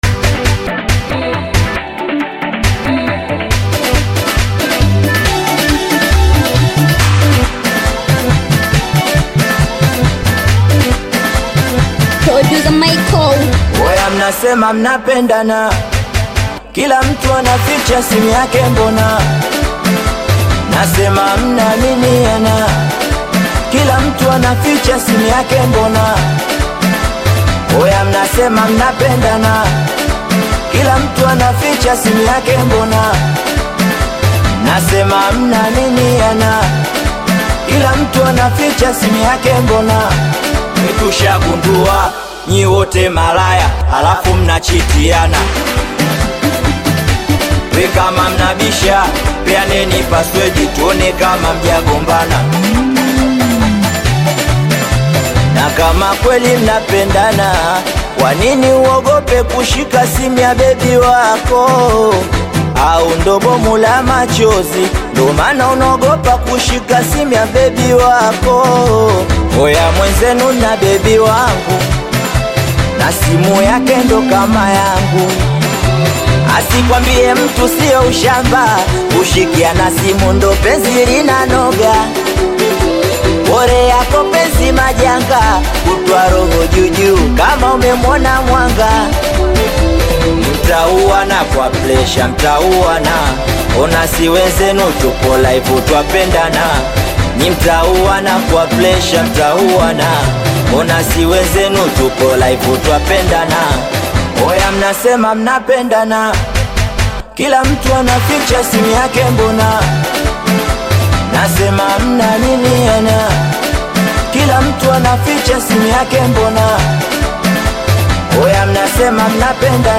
Tanzanian Bongo Flava Singeli
Singeli song